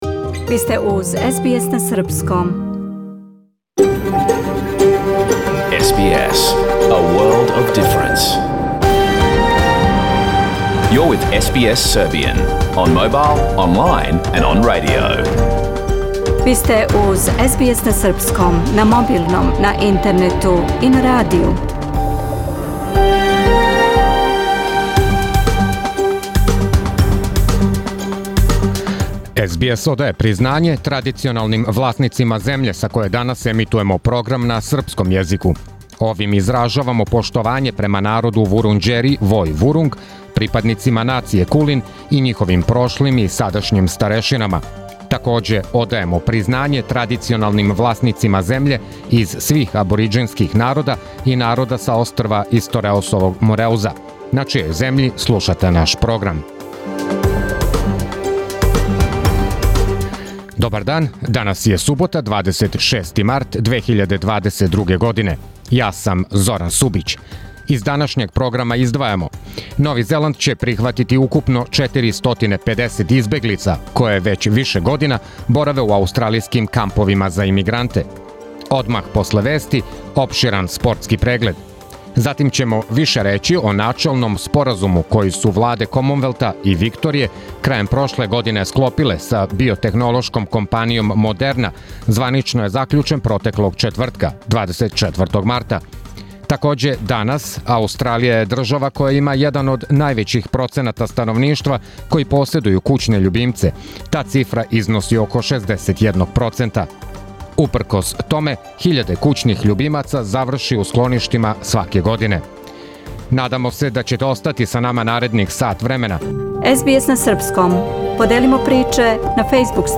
Програм емитован уживо 26. марта 2022. године
Ако сте пропустили нашу емисију, сада можете да је слушате у целини као подкаст, без реклама.